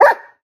Minecraft Version Minecraft Version 1.21.5 Latest Release | Latest Snapshot 1.21.5 / assets / minecraft / sounds / mob / wolf / sad / bark1.ogg Compare With Compare With Latest Release | Latest Snapshot
bark1.ogg